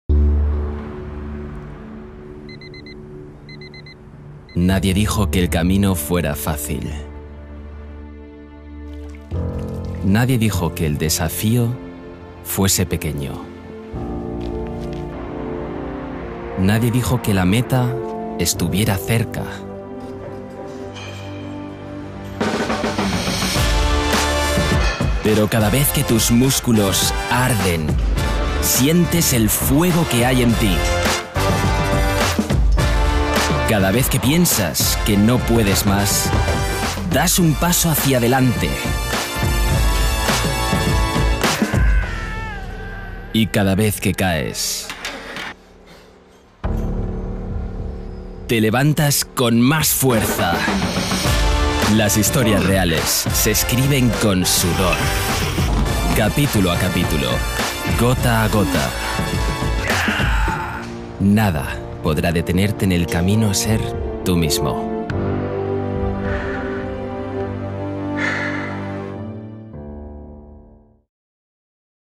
Spanischer Sprecher (Native Speaker aus Madrid) und Synchronschauspieler, junge, frische und dynamische Stimme.
kastilisch
Sprechprobe: Werbung (Muttersprache):
Versatile voice artist, Spanish native speaker, for all kind of productions and shows, voice-overs, advertisements, radio plays, dubbing, audio books, audio guides, image films...